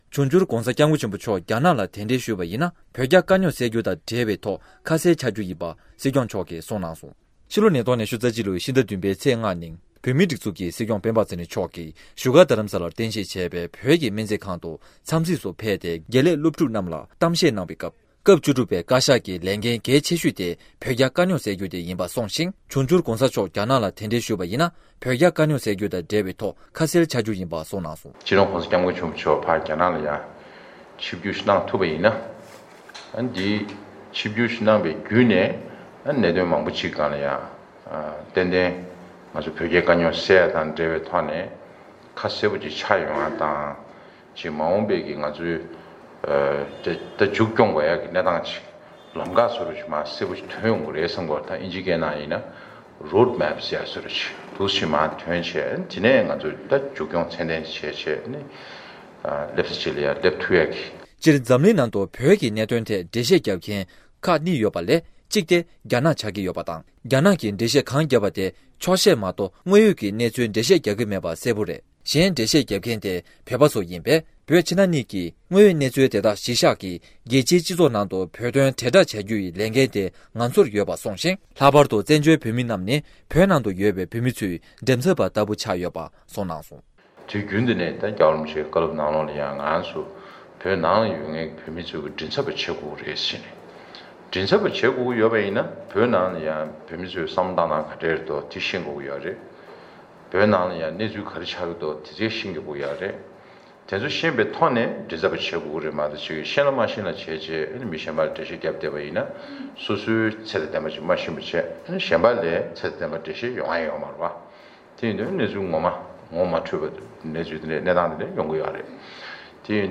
སྐབས་བཅུ་དྲུག་པའི་བཀའ་ཤག་གི་ཐུགས་འགན་གཙོ་བོ་ནི། བོད་རྒྱའི་དཀའ་རྙོག་སེལ་ཐབས་བྱ་རྒྱུ་ཡིན་པར་གསུངས། སྲིད་སྐྱོང་སྤེན་པ་ཚེ་རིང་མཆོག་གིས་བཞུགས་སྒར་རྡ་རམ་ས་ལར་རྟེན་གཞི་བྱས་པའི་བོད་ཀྱི་སྨན་རྩིས་ཁང་དུ་གསུང་བཤད་གནང་བའི་སྐབས།